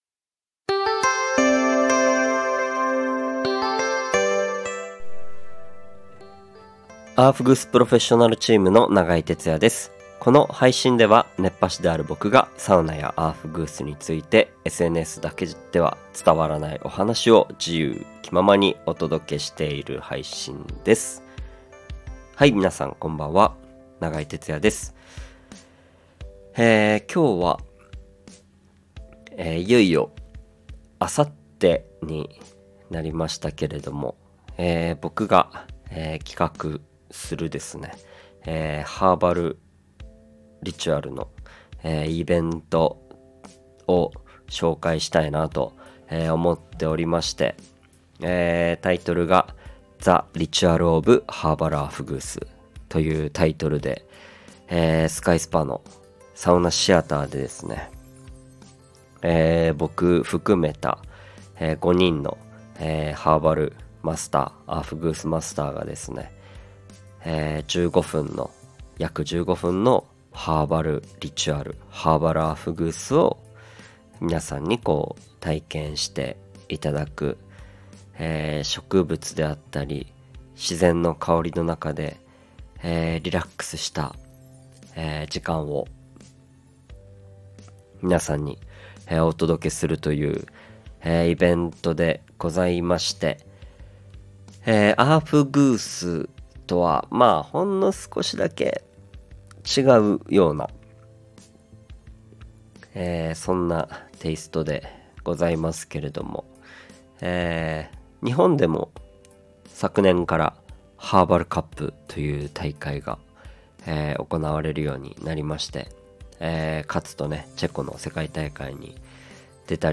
熱波師♨がサウナやアウフグースの話をするラジオ